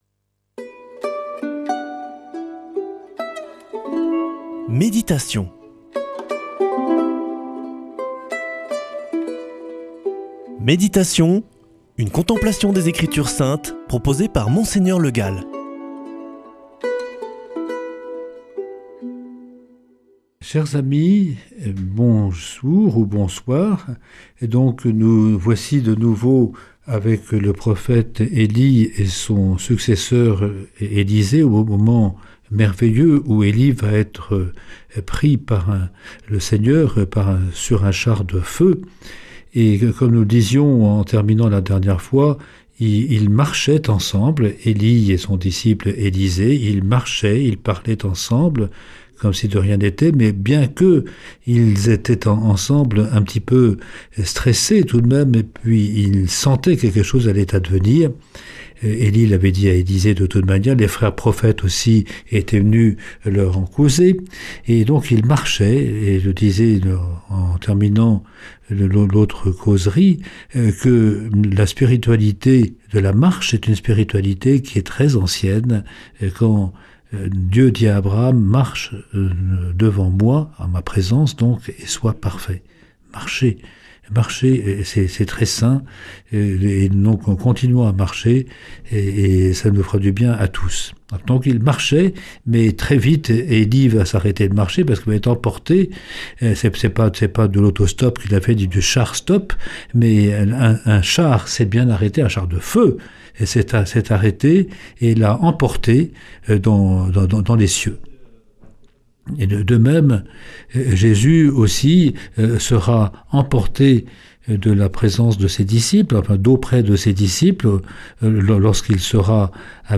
Méditation avec Mgr Le Gall
Une émission présentée par